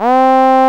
VOICE C3 F.wav